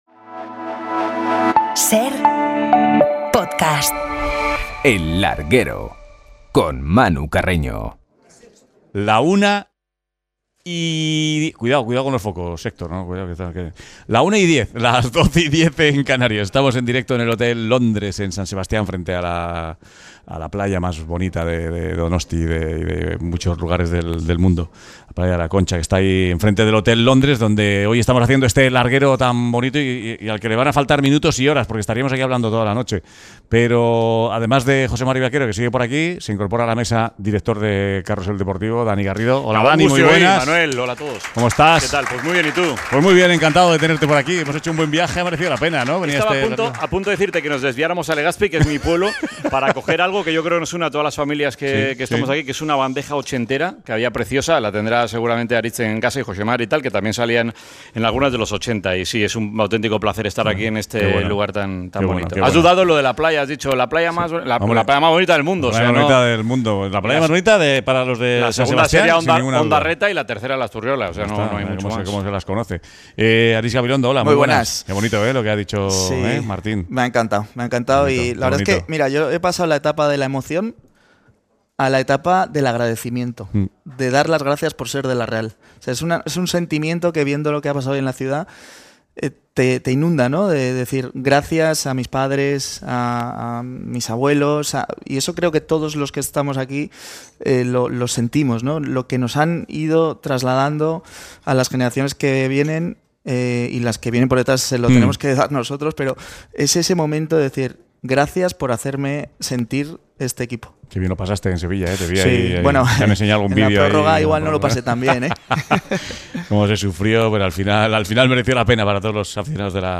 Entrevista a Martín Berasategui y la previa del Real Madrid - Alavés